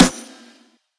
SZY_SNR.wav